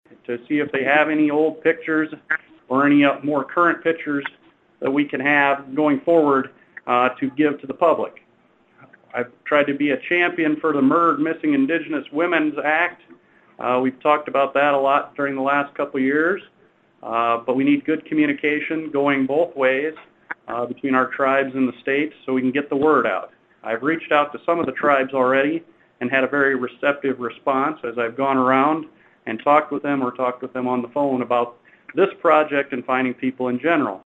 Ravnsborg says he’s also reached out to the Native American Tribes in the state.